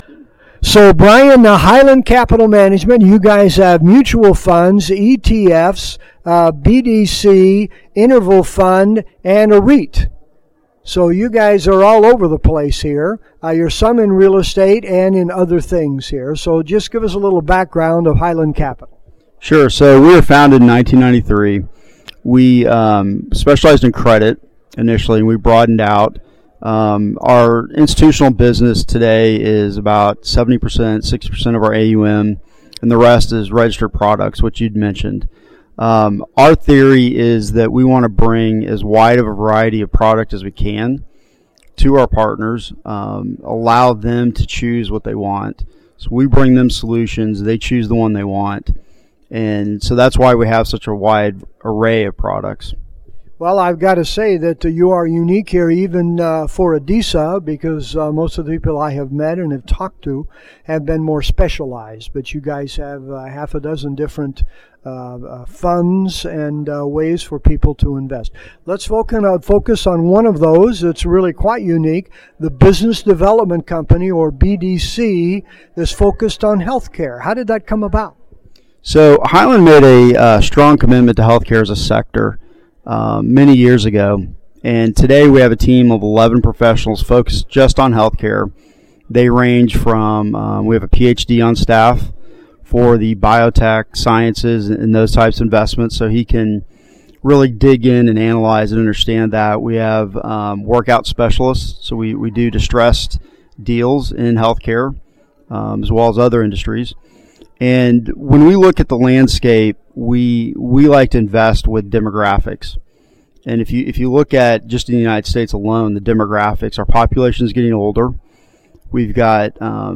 This interview was done at the ADISA Conference in San Diego